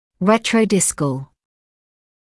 [ˌretrəu’dɪskl][ˌрэтроу’дискл]ретродисковый, расположенный кзади от диска (также retrodiskal)